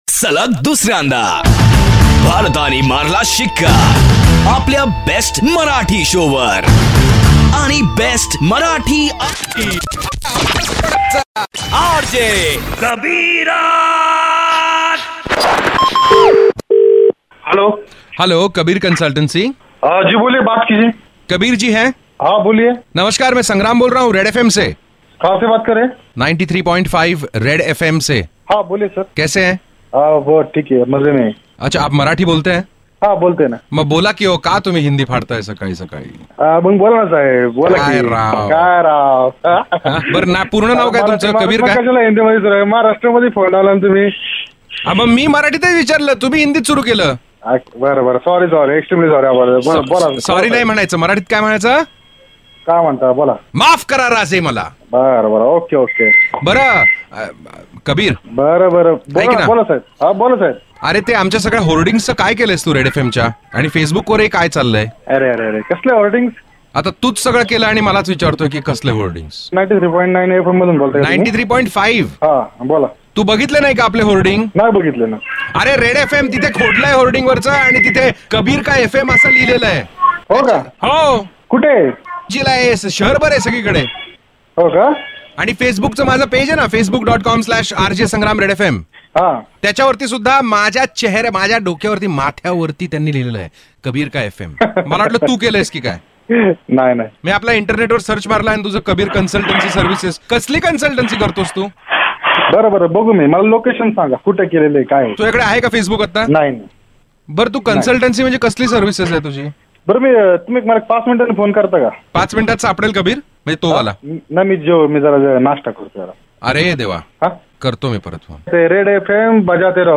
India Ka no. 1 Marathi Radio Show.